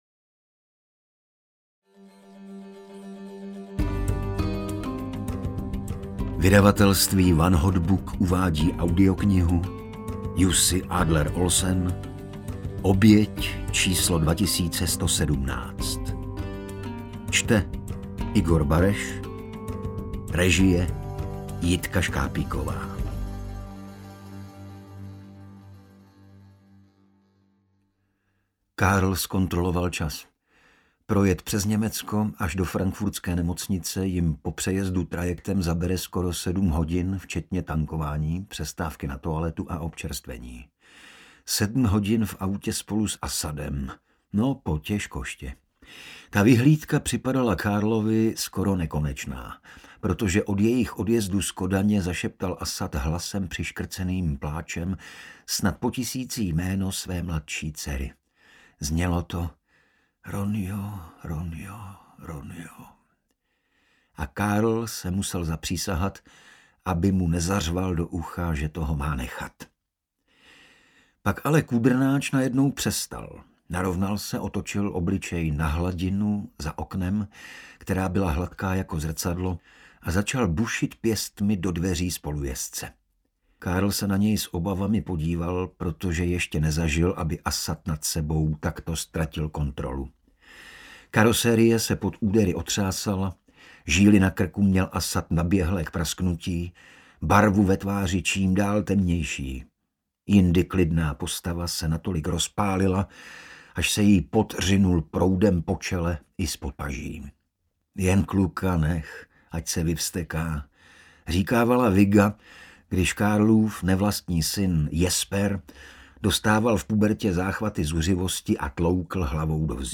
Oběť 2117 audiokniha
Ukázka z knihy
• InterpretIgor Bareš